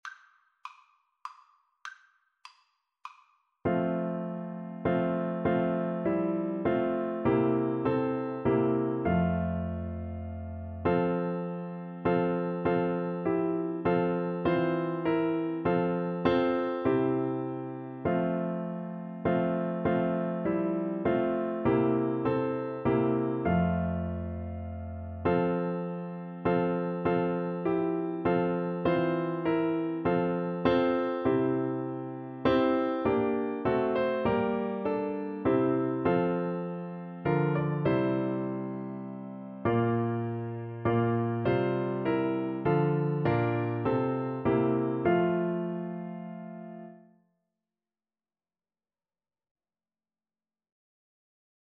3/4 (View more 3/4 Music)